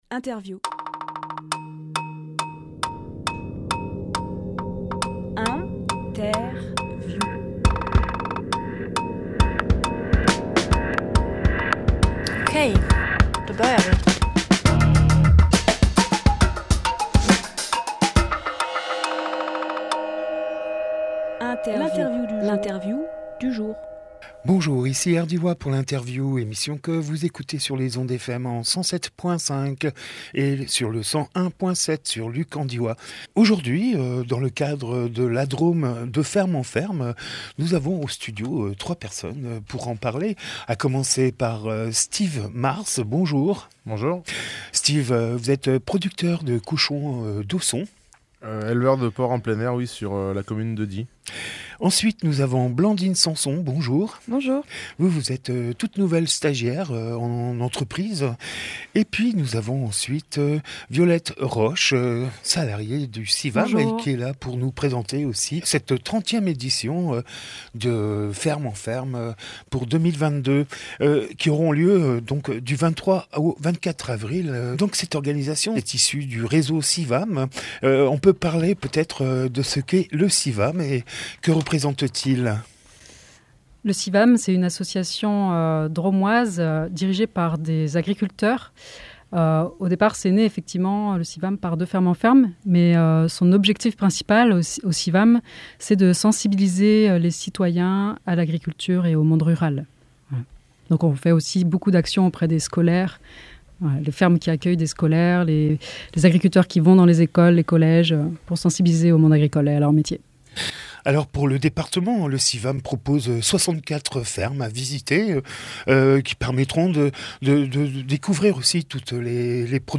Emission - Interview La Drôme de ferme en ferme 2022 Publié le 18 avril 2022 Partager sur…
14.04.22 Lieu : Studio RDWA Durée